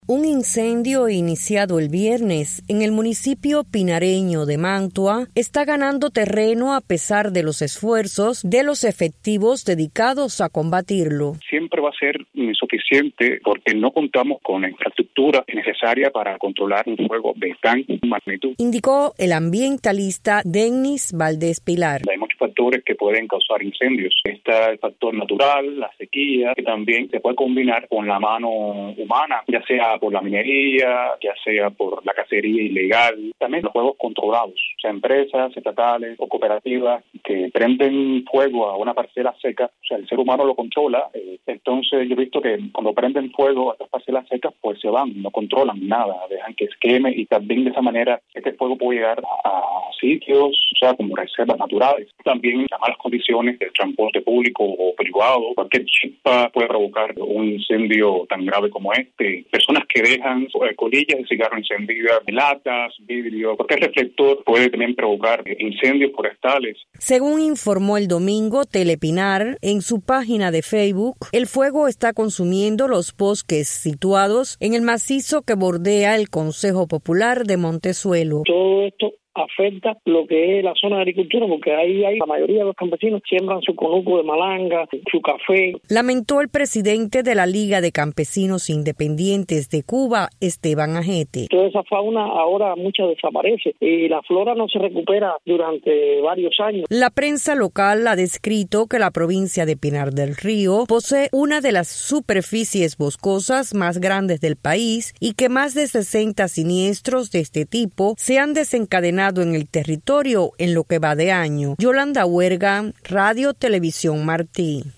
Informe sobre el incendio en Pinar del Río